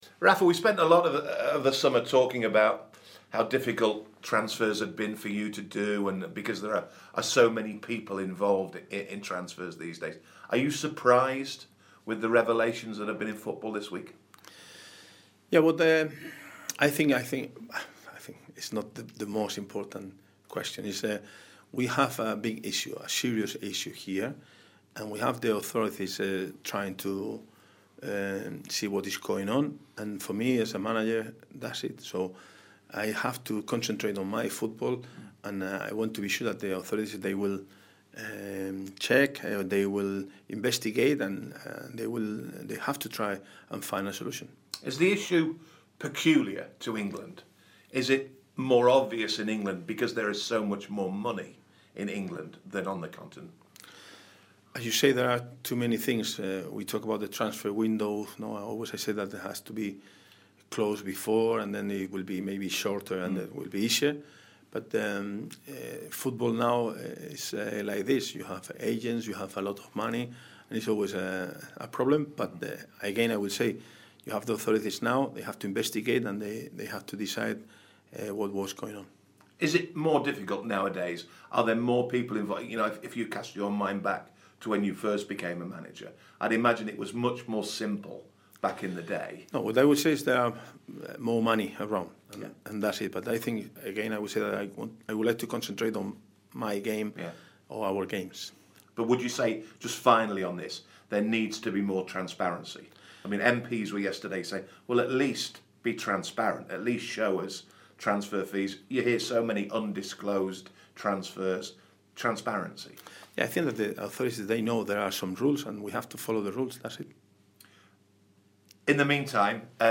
Rafa Benitez speaks to BBC Newcastle pre-Rotherham